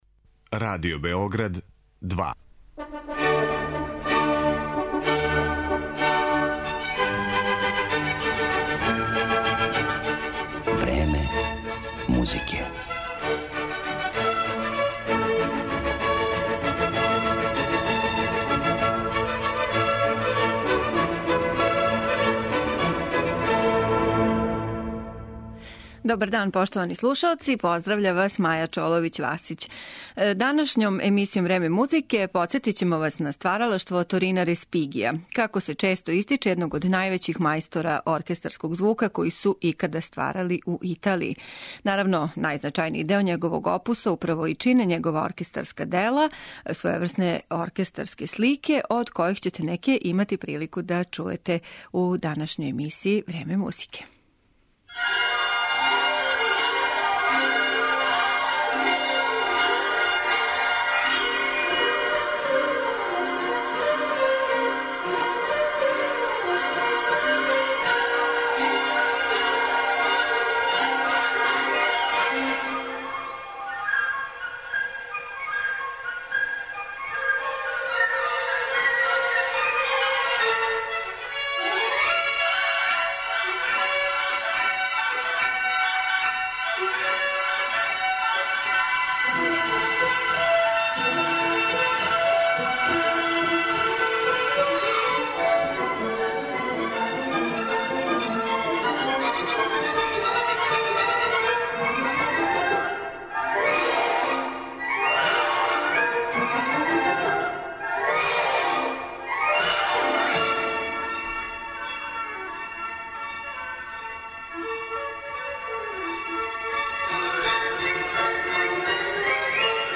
Део тог опуса чине познате Римске фонтане, Римске пиније, свита Птице и Античке игре и aрије, које ћете данас слушати у извођењу водећих светских оркестара и диригената.